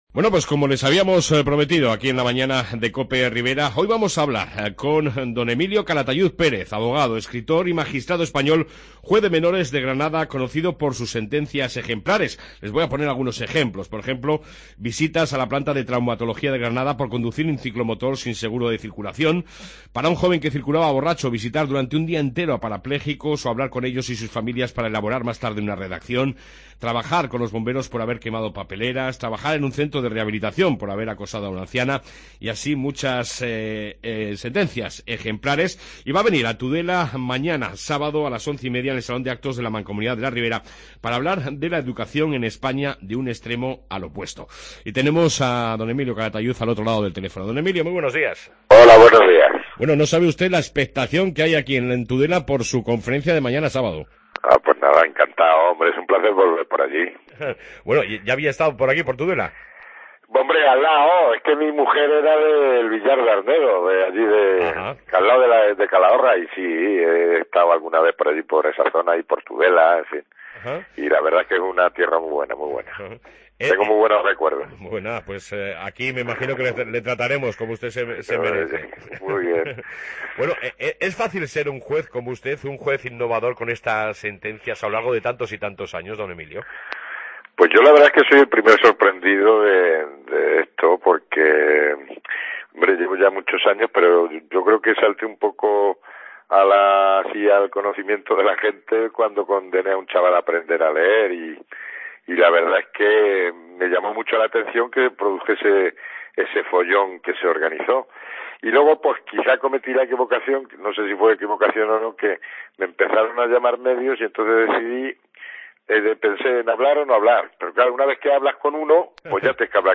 Redacción digital Madrid - Publicado el 20 feb 2015, 13:49 - Actualizado 14 mar 2023, 00:09 1 min lectura Descargar Facebook Twitter Whatsapp Telegram Enviar por email Copiar enlace Entrevista con D:Emilio Calatayud Pérez, abogado, escritor y magistrado español, juez de menores de Granada, conocido por sus sentencias ejemplares.